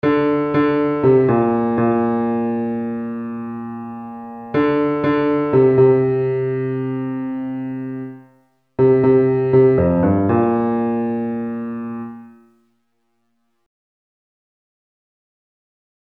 Key written in: B♭ Major
Type: Barbershop
Each recording below is single part only.